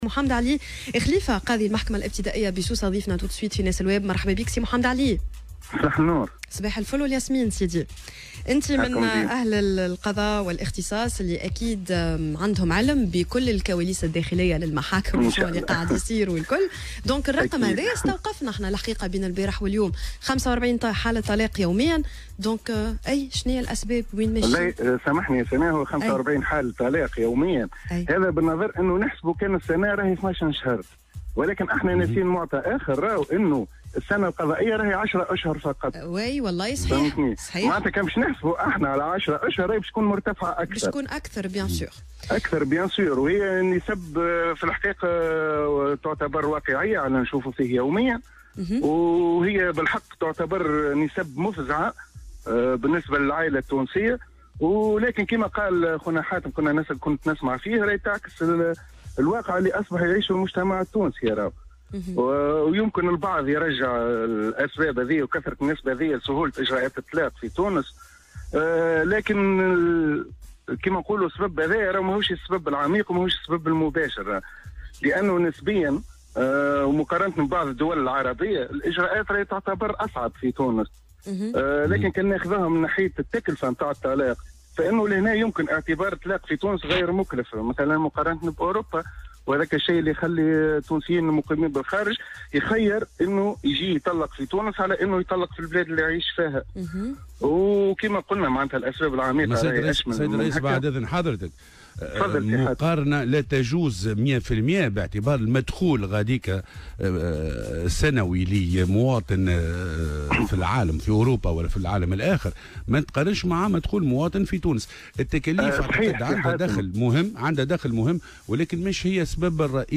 وقد وصف محمد علي خليفة، قاض بالمحكمة الابتدائية بسوسة في مداخلة له اليوم في برنامج "صباح الورد" على "الجوهرة أف أم" هذه الأرقام بالمفزعة لكنه أكد أنها تعكس الواقع الذي أصبح يعيشه المجتمع التونسي.